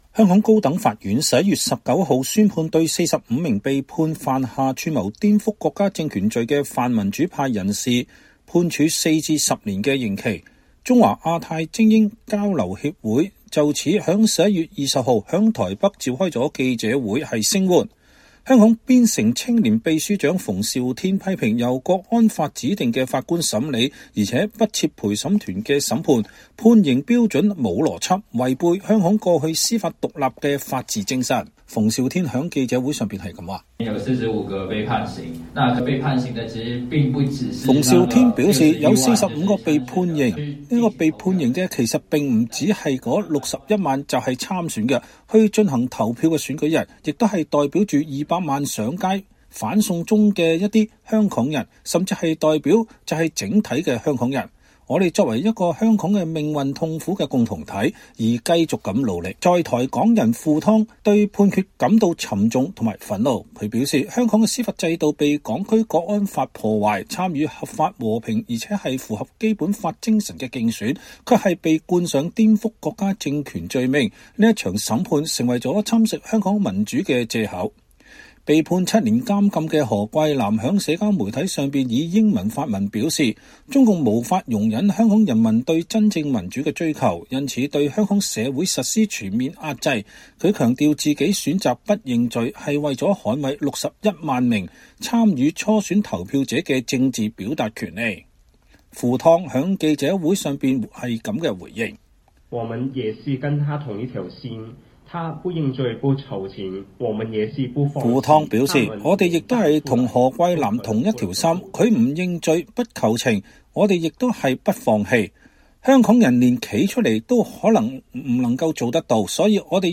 中華亞太精英交流協會就此於11月20日在台北召開記者會聲援。